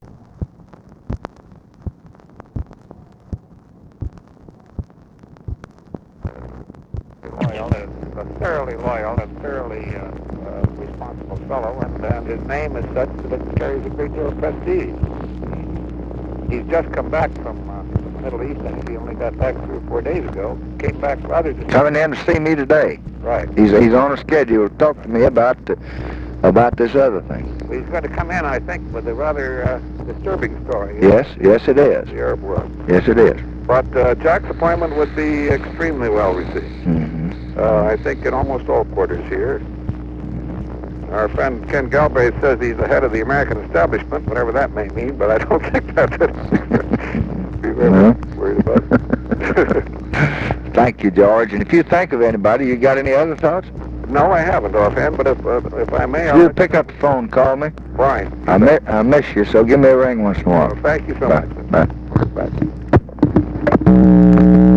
Conversation with GEORGE BALL, April 25, 1968
Secret White House Tapes